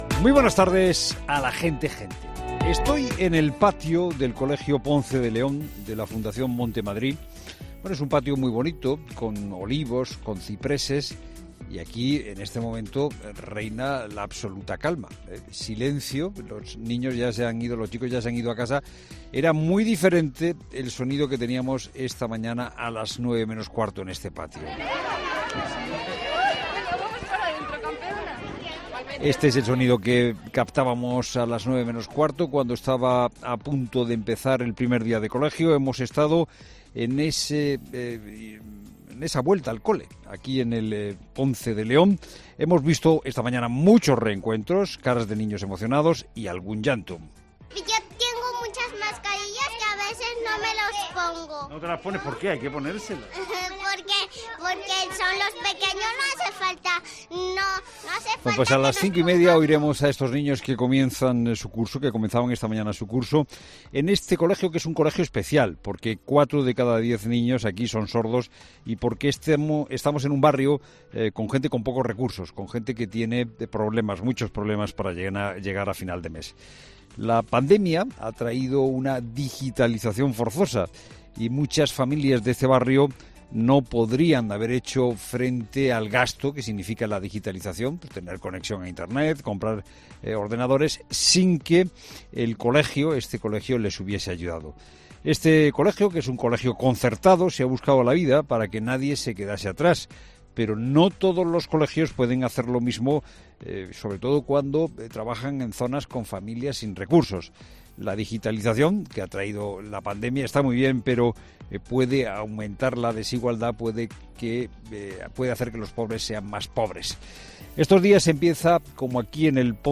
Monólogo
reflexiona sobre el inicio escolar frente a las puertas del Colegio Ponce de León, en Madrid